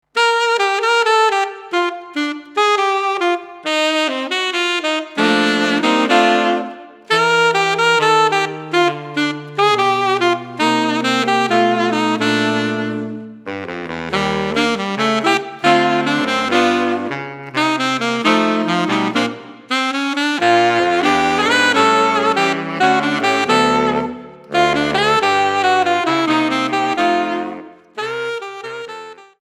4 Saxophones (2ATB)